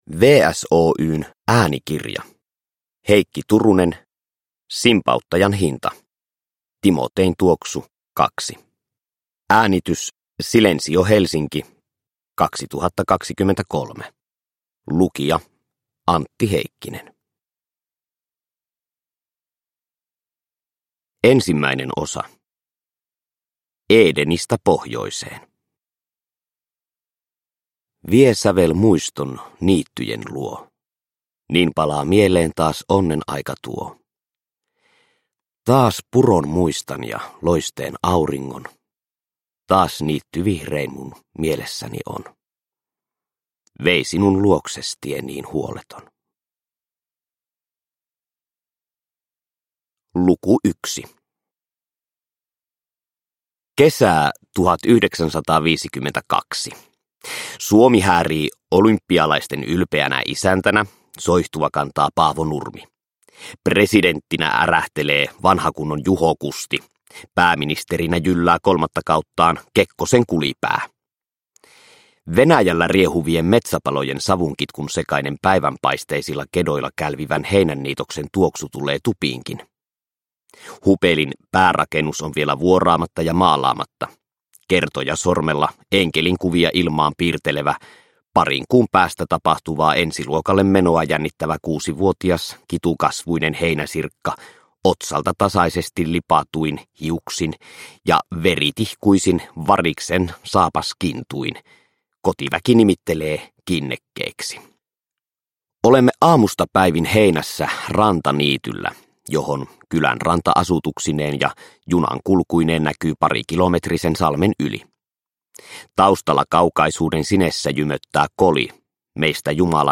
Simpauttajan hinta – Ljudbok – Laddas ner